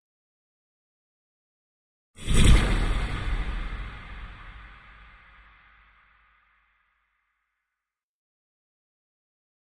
Impact Muted Hit Reverb - Version 4